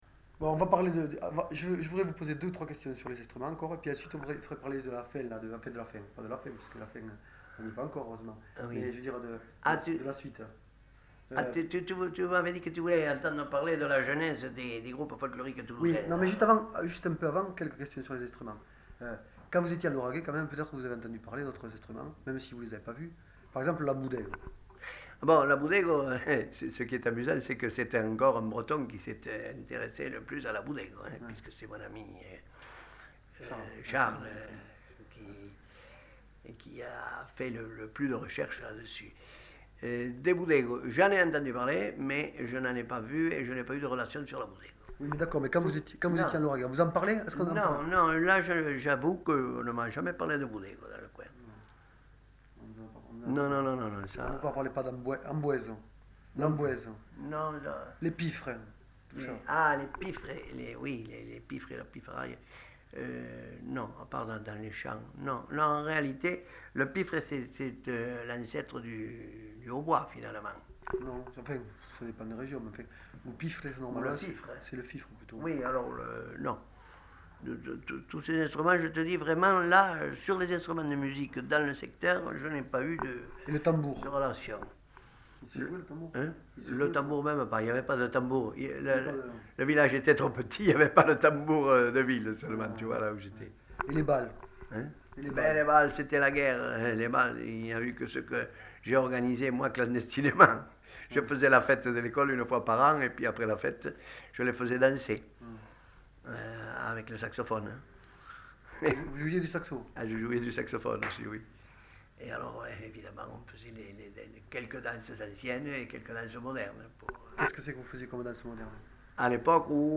Genre : récit de vie
Instrument de musique : flûte à bec